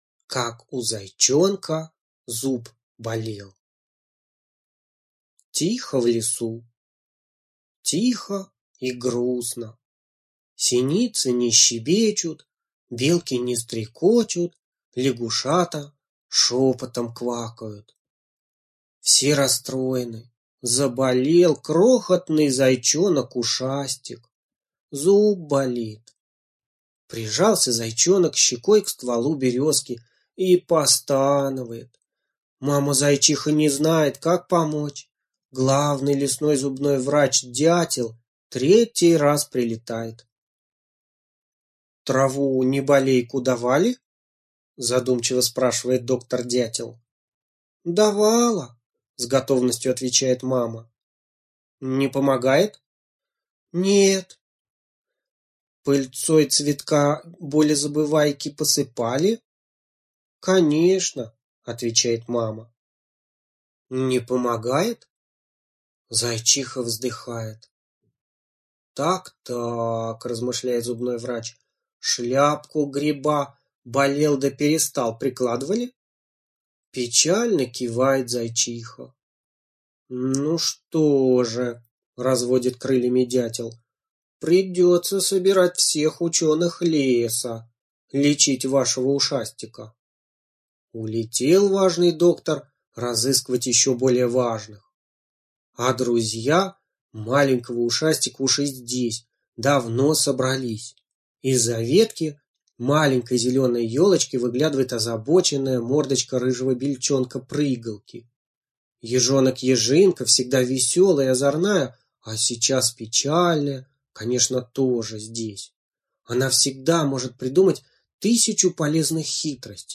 Слушайте Как у зайчонка зуб болел - аудиосказка Абрамцевой Н. Сказка о том, как у зайчонка заболел зуб.